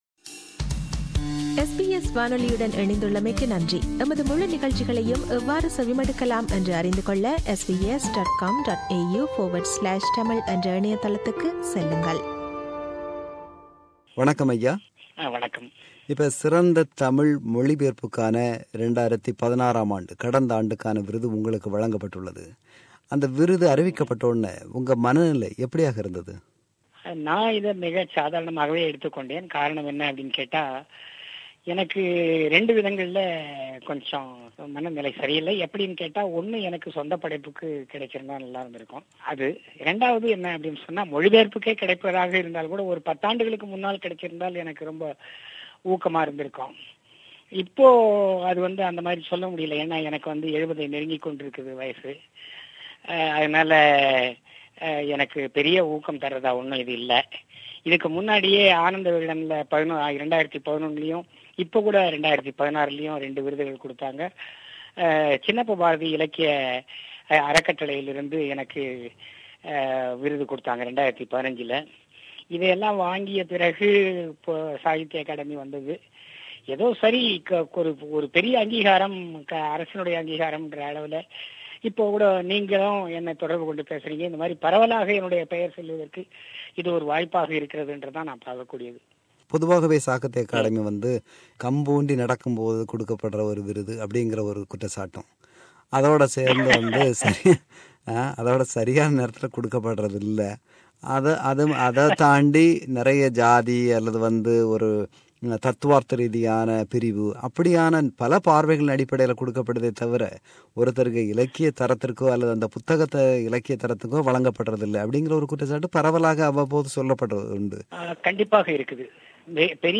அவரோடு ஒரு சந்திப்பு.